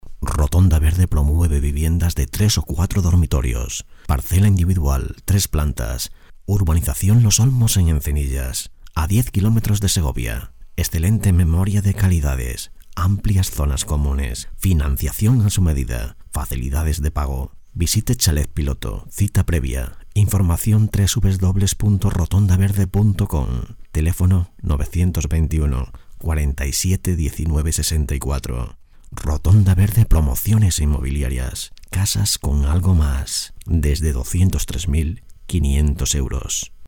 Sprecher spanisch.
kastilisch
spanish voice over artist.